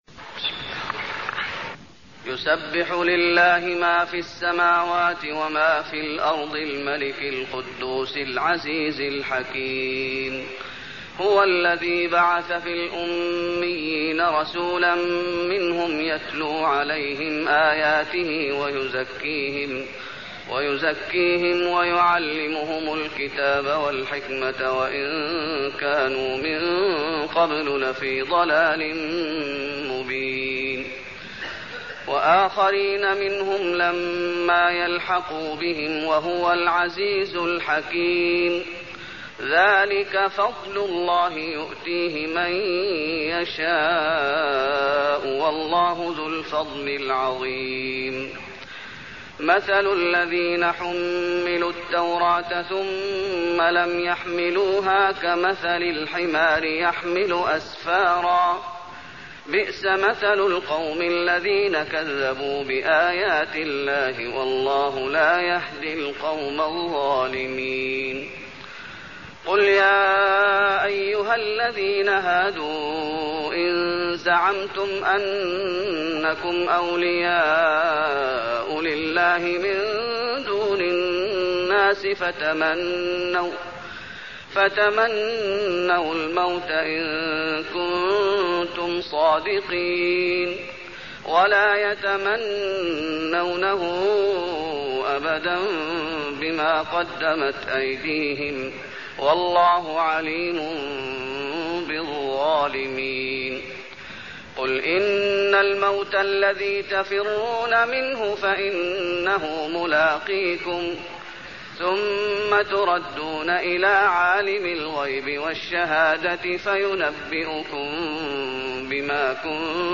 المكان: المسجد النبوي الجمعة